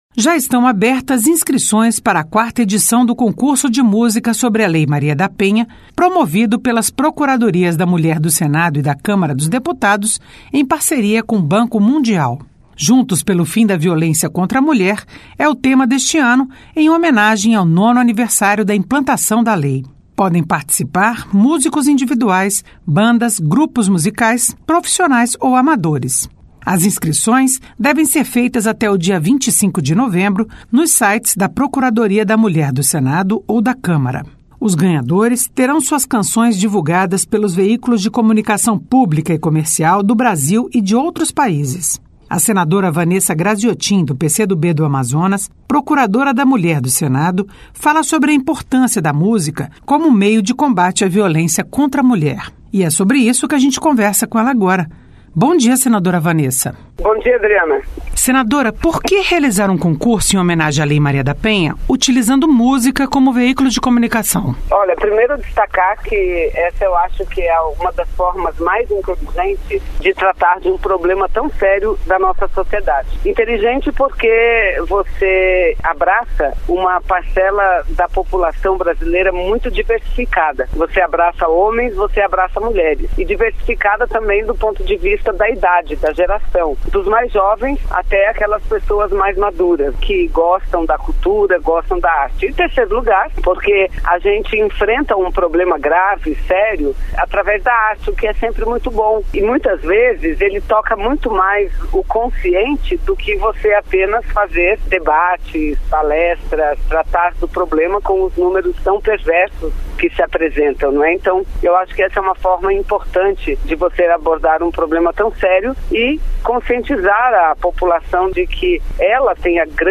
O Senado nos quatro cantos do País. Entrevistas regionais, notícias e informações sobre o Senado Federal